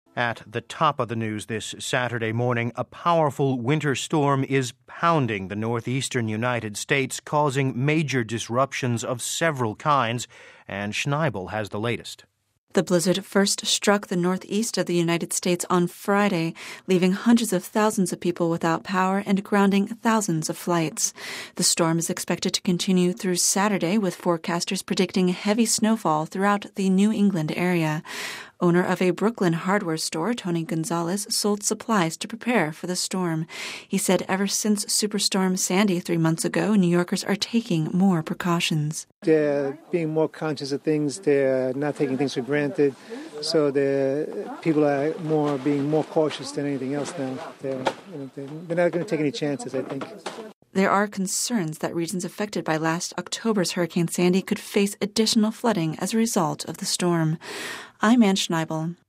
(Vatican Radio) Regions of the United States east coast affected by last year’s hurricane Sandy is being struck by a winter storm. The blizzard struck the north east of the United States on Friday leaving hundreds of thousands of people without power, and grounding thousands of flights.